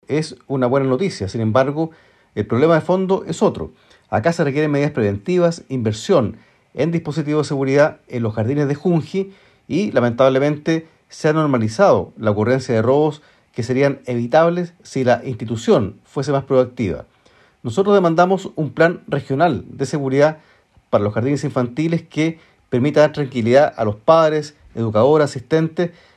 Por su parte, el diputado del Partido Comunista, Luis Cuello, si bien, consideró la detención como una buena noticia, advirtió que aún hace falta un plan regional de seguridad para los jardines infantiles.